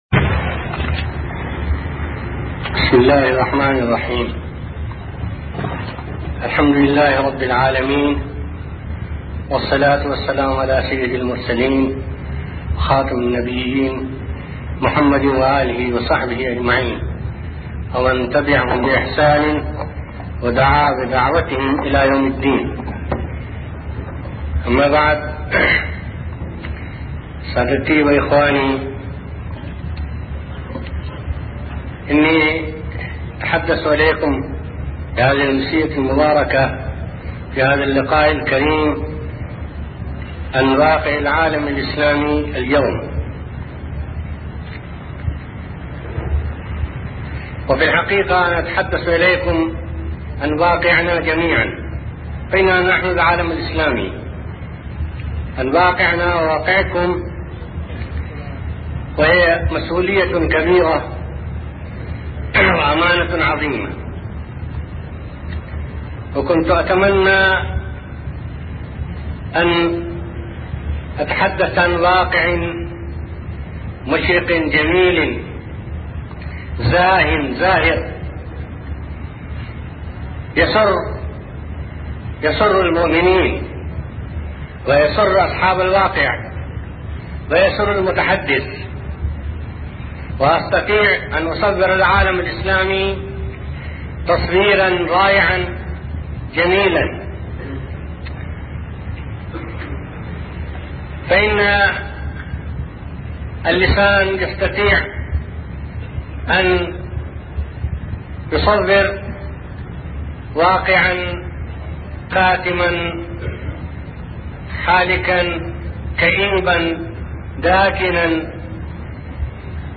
أرشيف الإسلام - ~ أرشيف صوتي لدروس وخطب ومحاضرات الشيخ أبو الحسن الندوي